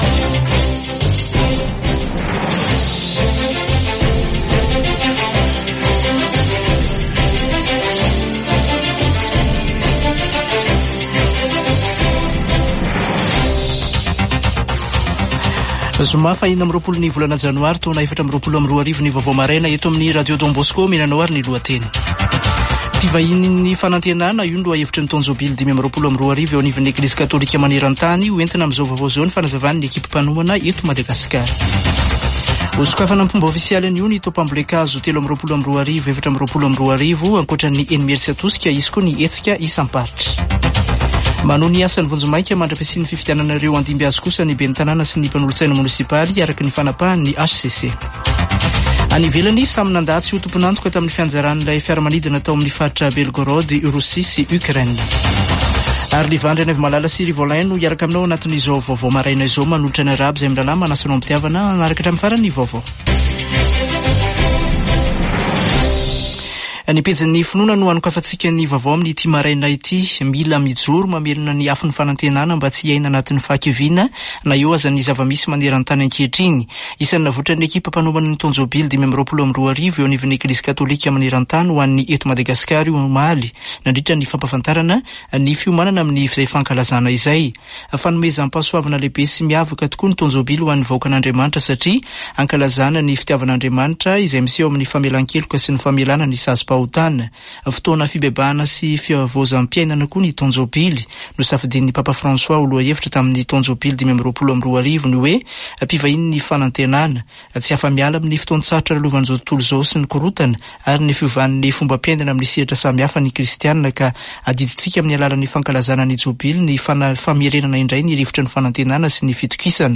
[Vaovao maraina] Zoma 26 janoary 2024